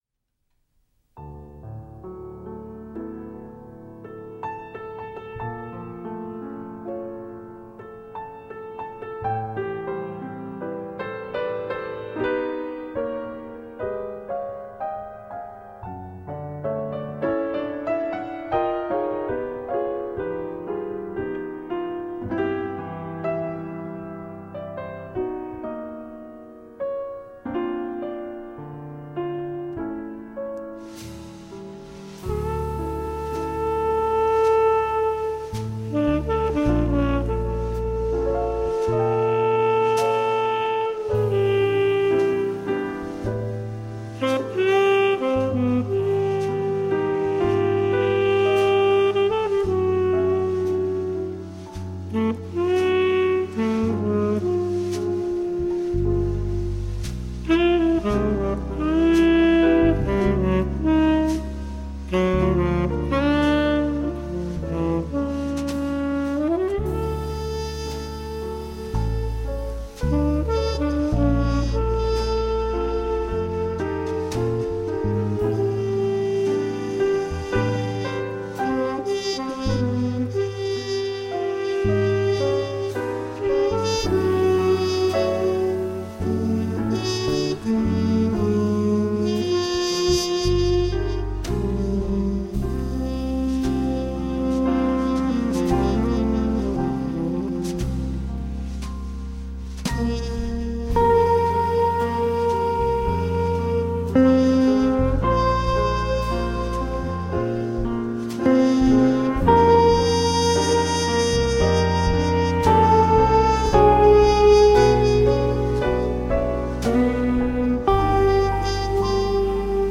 Funky jazz in a live setting.
Unedited. Unrehearsed.
Then right after that comes a beautiful ballad
and the tenor saxophone will make you cry...
Bass.
Drums.
Trumpet/Flügelhorn.
Saxophone.
Electric guitar.
Grand Piano, Rhodes, Prophet 5.
Tagged as: Jazz, Downtempo